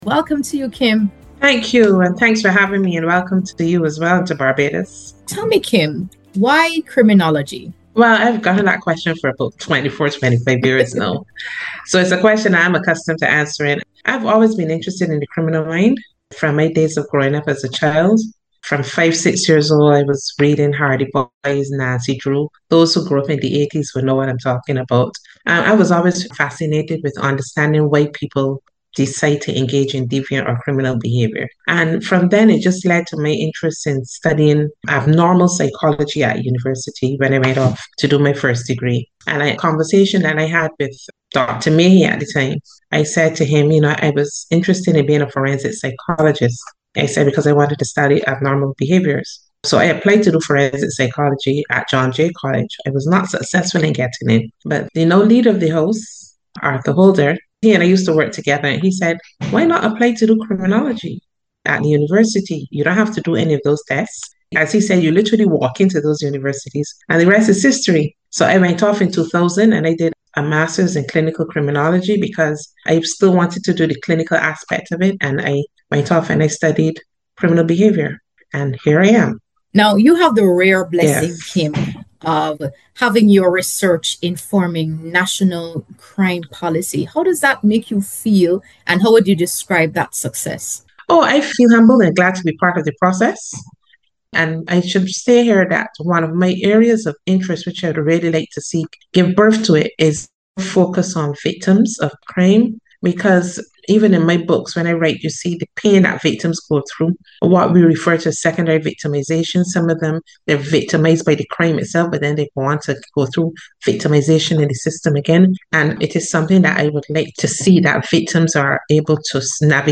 A thoughtful conversation on leadership, justice, and the role of knowledge in shaping safer, stronger communities.